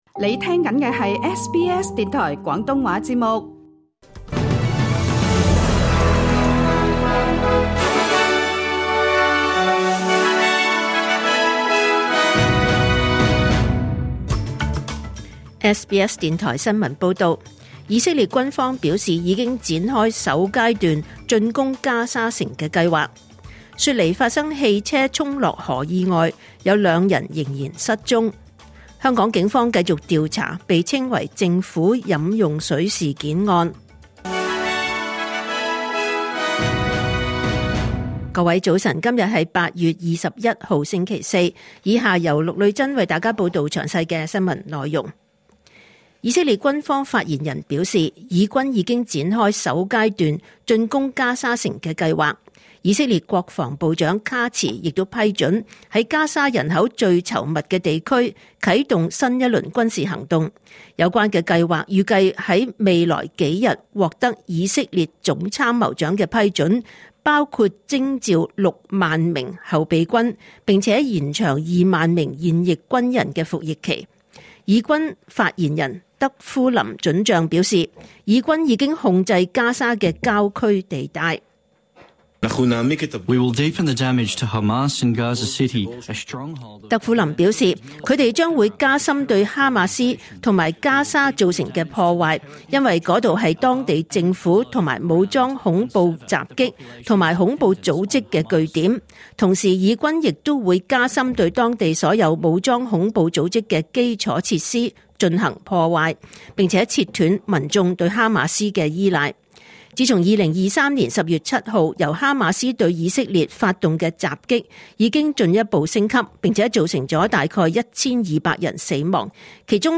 2025年8月21日SBS廣東話節目九點半新聞報道。